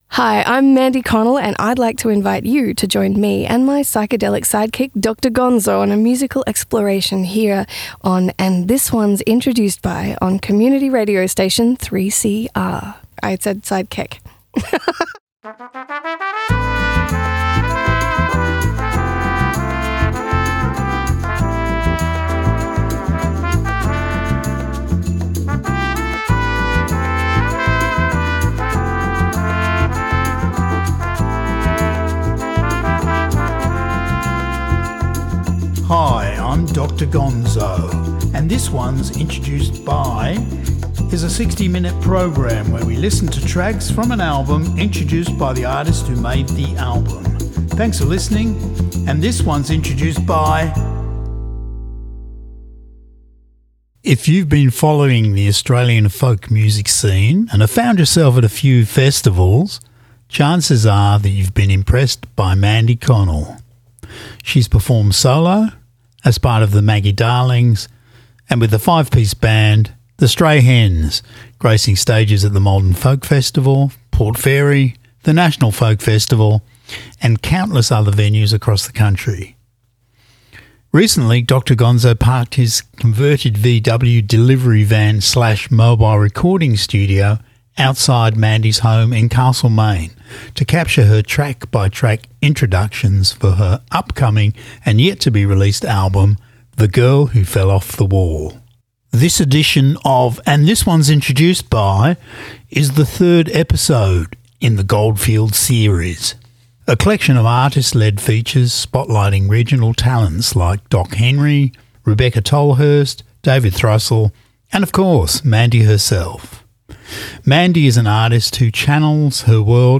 Then, we’ll dive right into the first track, with Mick offering his personal insights along the way.